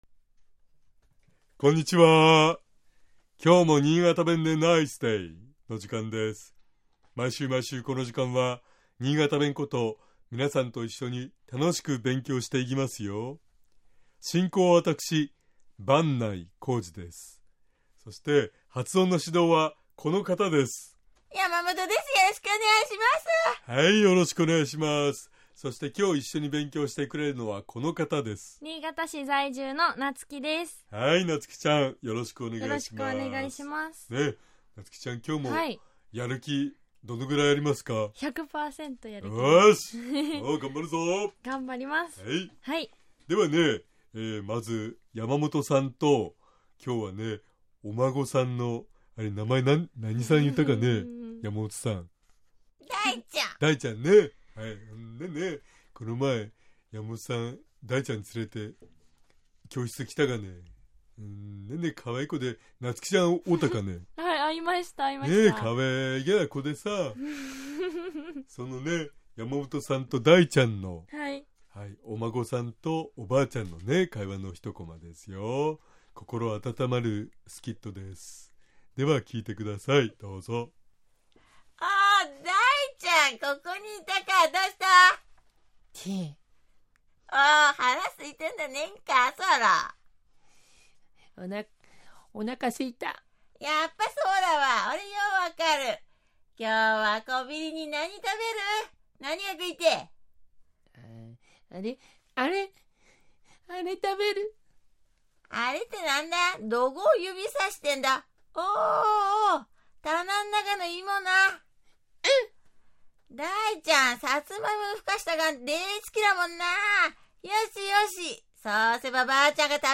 BSN新潟放送｜ラジオ｜今すぐ使える新潟弁 by Podcast｜10月28日（月）今日も新潟弁でナイスデイ！